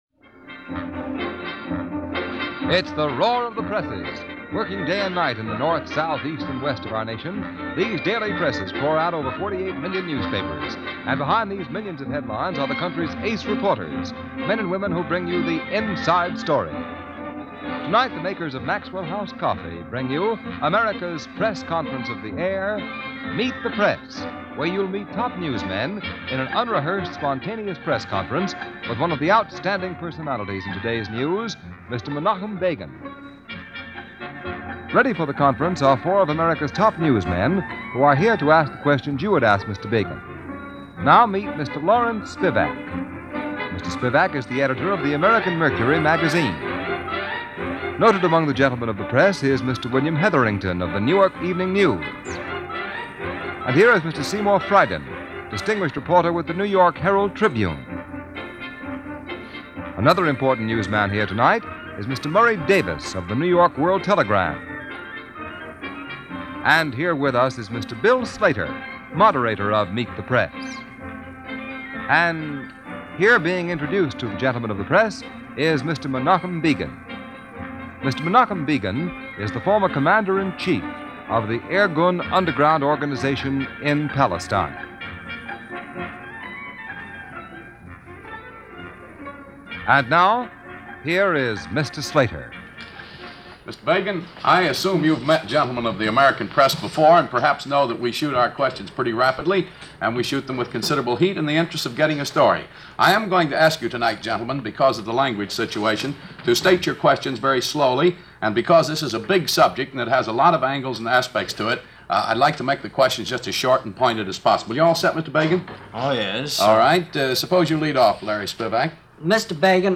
Today it’s an early Meet The Press interview with Menachem Begin, recorded on December 12th 1948.